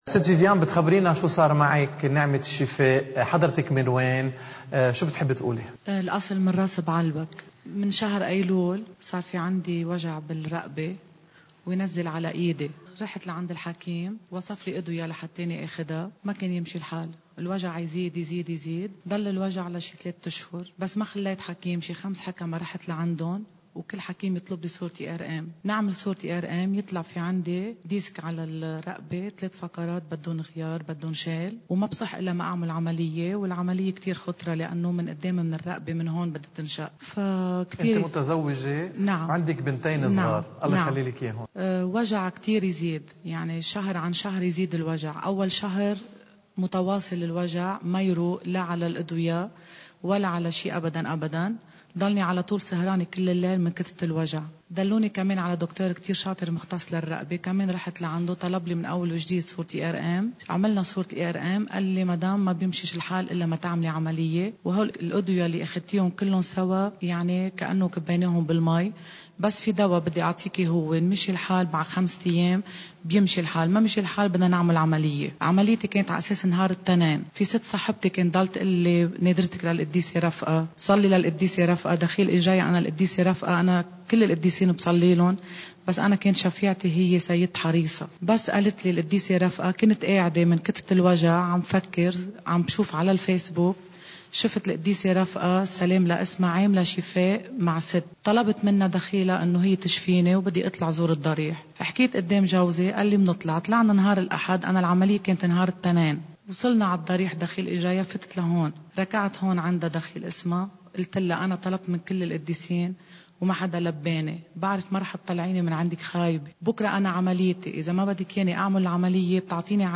مقتطف من حديث